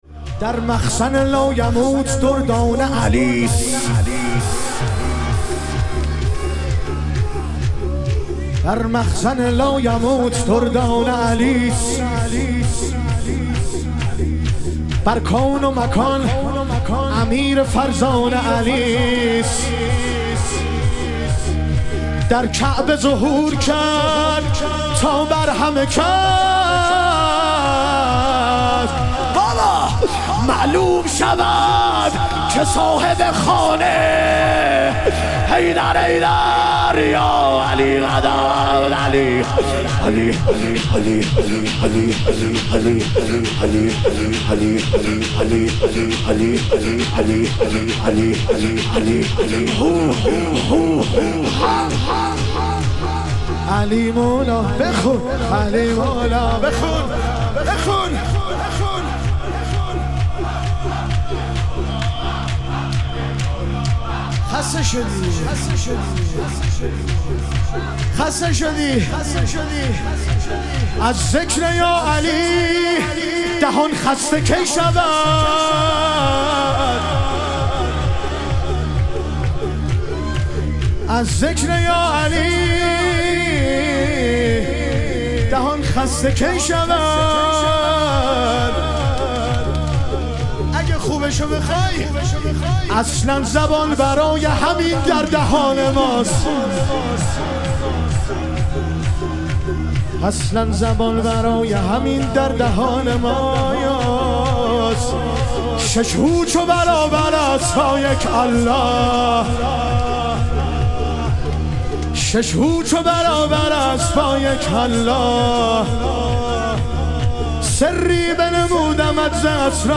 شهادت حضرت مسلم علیه السلام - شور